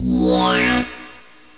switch_on.wav